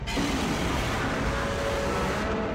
File:G-1.0 Initial Form Roar.mp3
train whistle
G-1.0_Initial_Form_Roar.mp3